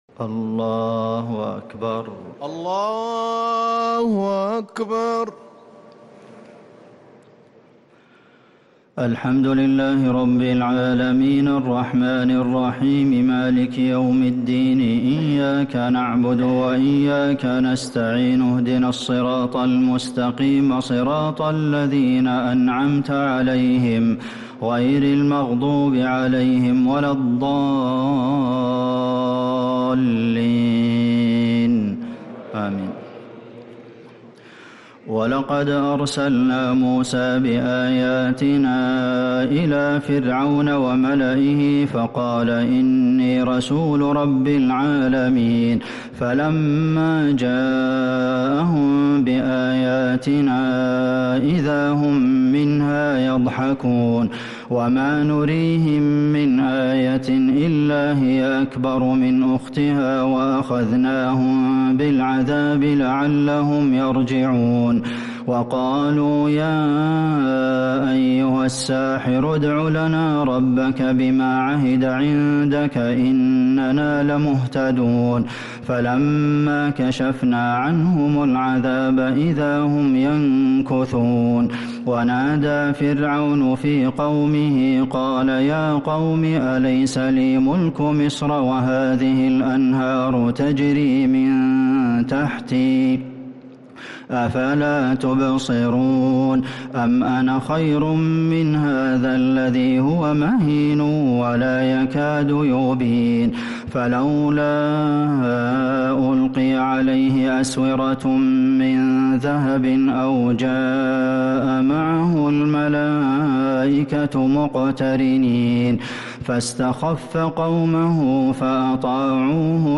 تراويح ليلة 26 رمضان 1446هـ سورة الزخرف (46-89) وسورة الدخان كاملة | taraweeh 26th night Ramadan 1446H surah az-Zukhruf and ad-Dukhan > تراويح الحرم النبوي عام 1446 🕌 > التراويح - تلاوات الحرمين